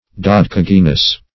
Search Result for " dodecagynous" : The Collaborative International Dictionary of English v.0.48: Dodecagynian \Do*dec`a*gyn"i*an\, Dodecagynous \Do`de*cag"y*nous\, a. (Bot.) Of or pertaining to the Dodecagynia; having twelve styles.